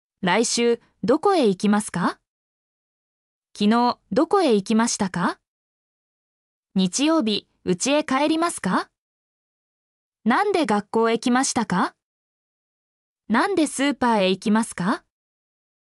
mp3-output-ttsfreedotcom_znYMc5Tc.mp3